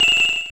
Sound effect of "Star Coin" in New Super Mario Bros.
NSMB_Star_Coin.oga.mp3